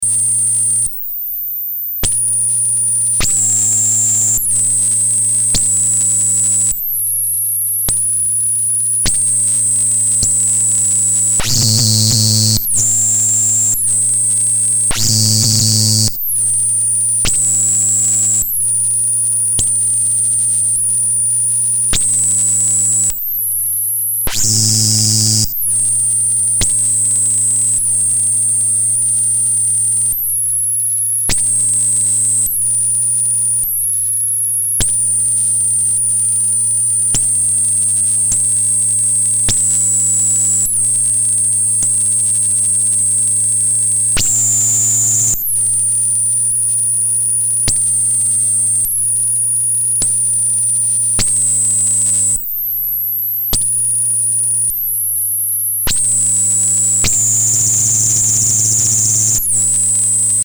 I can get some lovely sounds when it’s set but it has a really strong digital quality that I can’t say I vibe with.